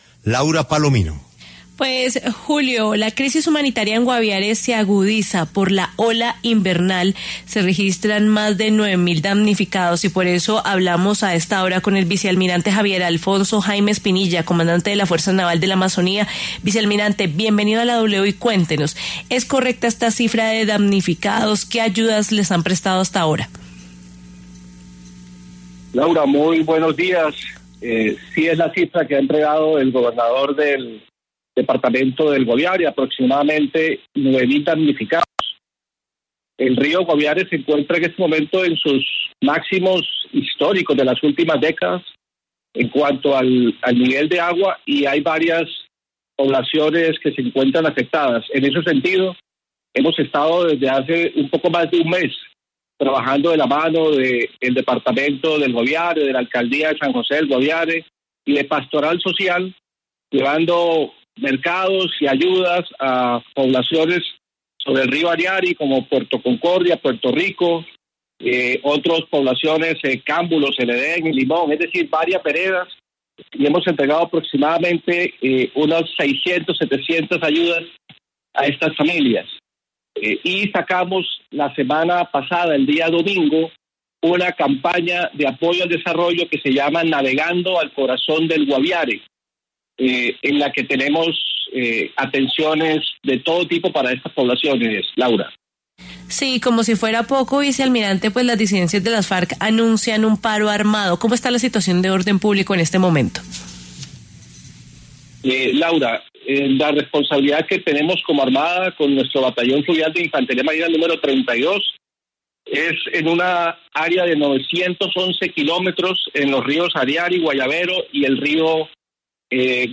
El vicealmirante Javier Alfonso Jaimes Pinilla conversó con La W sobre la ola invernal que se vive en el Guaviare y la atención de las autoridades.